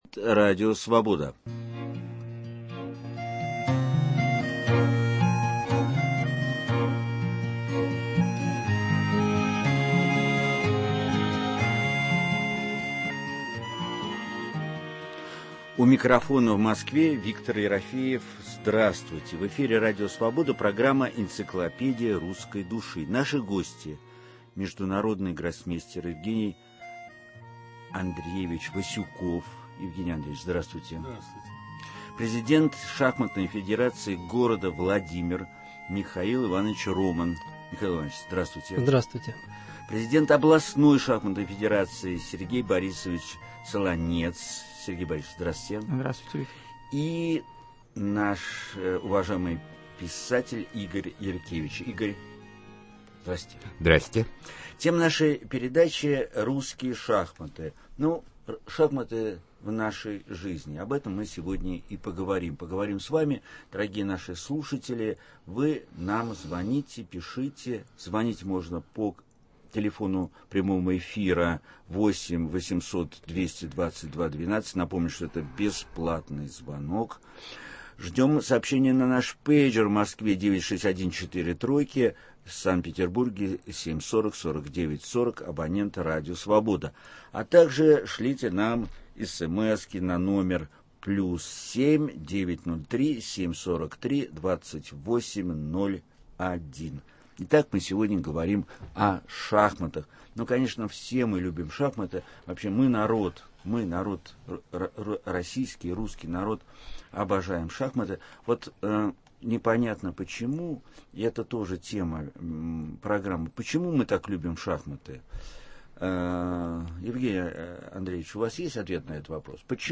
В прямом эфире мы поговорим о том, какую роль играют шашматы в современном мире. Наши эксперты - международный гроссмейстер Евгений Васюков, писатель Игорь Яркевич.